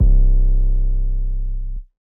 [808] Murda.wav